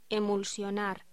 Locución: Emulsionar